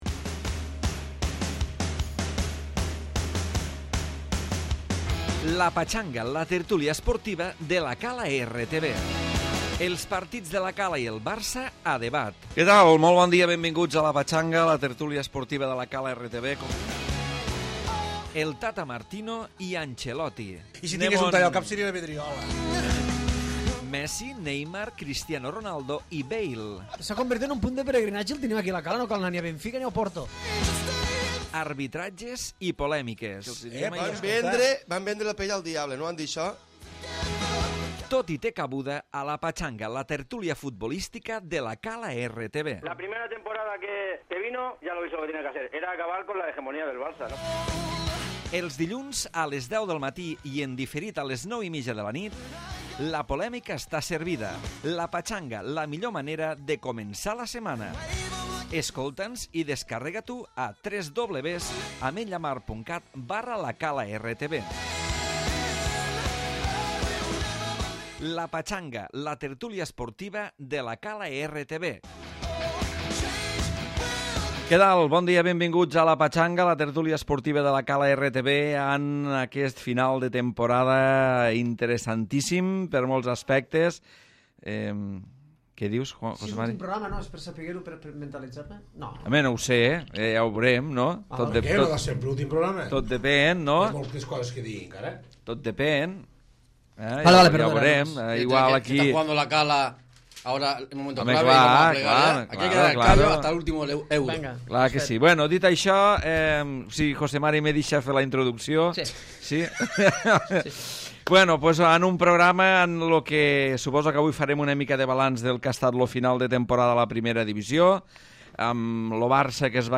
Tertúlia esportiva on s'analitza l'actualitat del 1er equip de La Cala i sobre tot el dia a dia del Barça i Madrid. Avui amb el final de temporada del barça i la propera Final de la Champions League.